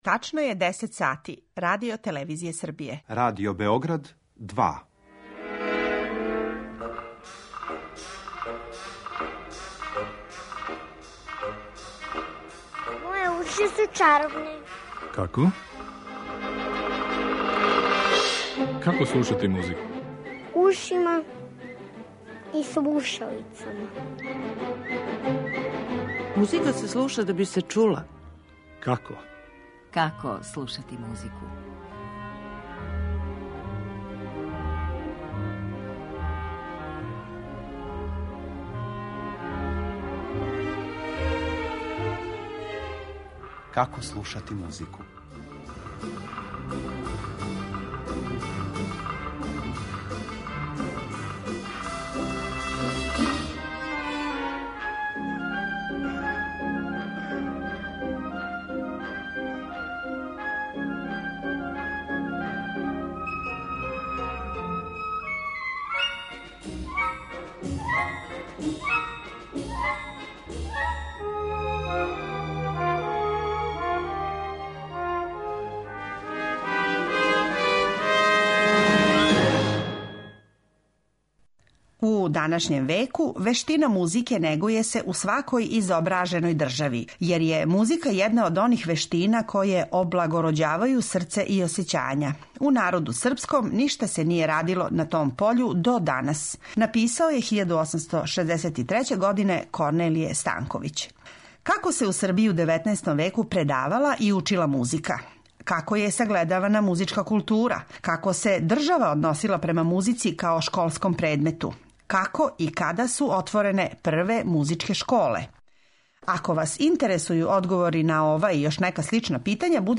У емисији ћете чути и пуно ретких снимака сачуваних у фонотеци Радио Београда.